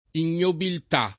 vai all'elenco alfabetico delle voci ingrandisci il carattere 100% rimpicciolisci il carattere stampa invia tramite posta elettronica codividi su Facebook ignobiltà [ in’n’obilt #+ ] o ignobilità [ in’n’obilit #+ ] s. f.